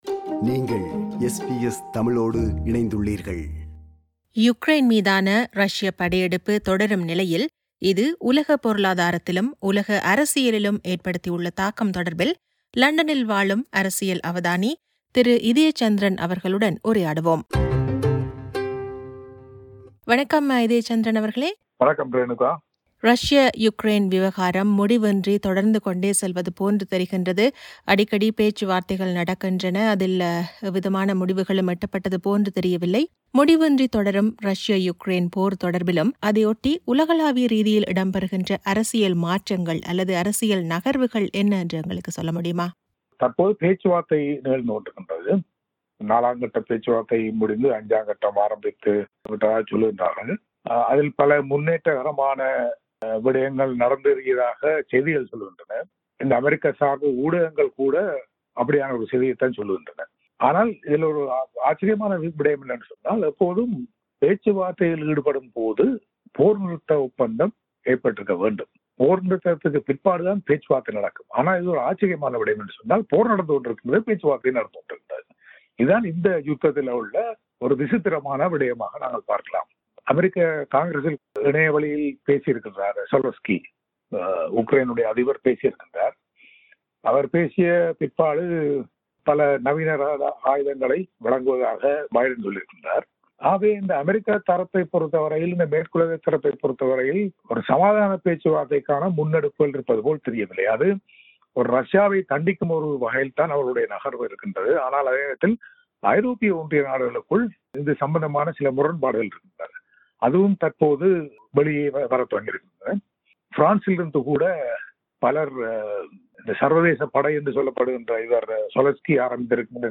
SBS Tamil